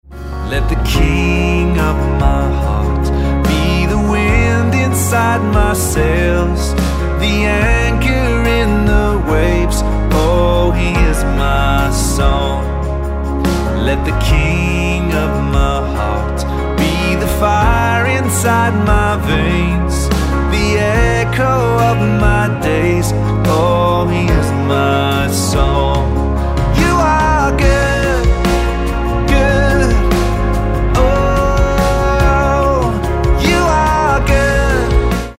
C#